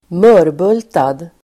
Ladda ner uttalet
Uttal: [²m'ö:rbul:tad]